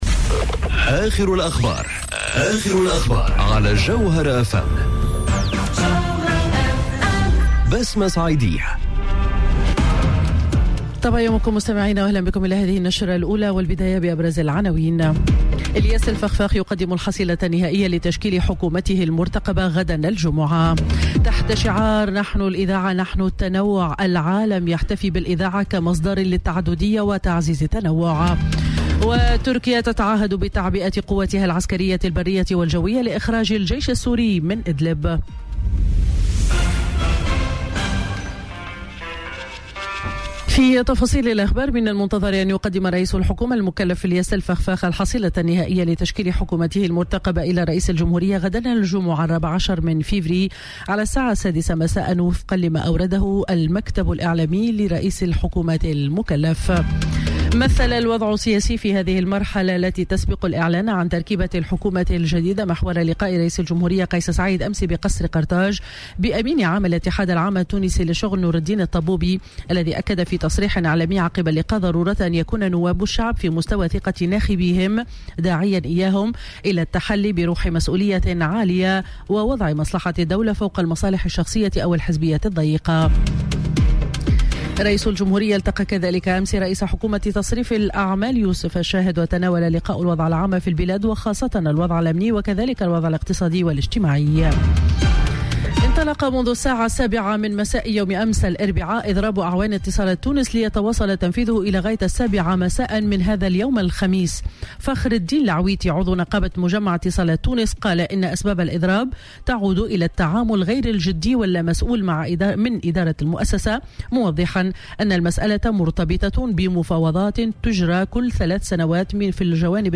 نشرة أخبار السابعة صباحا ليوم الخميس 13 فيفري 2020